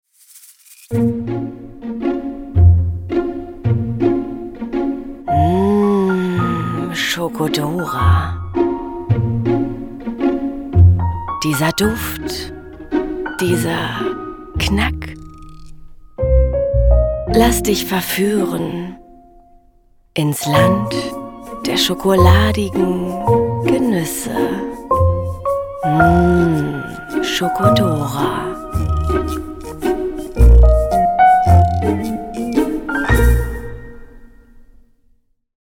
sehr variabel, hell, fein, zart, dunkel, sonor, souverän, plakativ, markant
Commercial (Werbung)